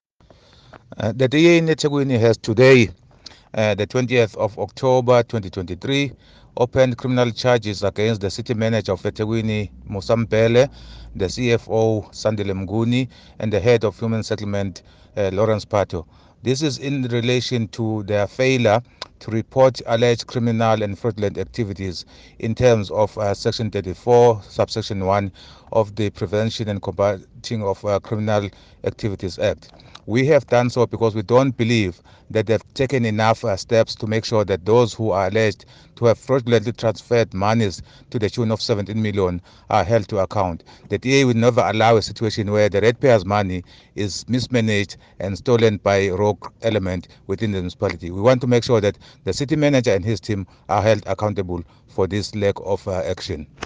IsiZulu soundbites by Councillor Thabani Mthethwa – DA eThekwini Caucus Leader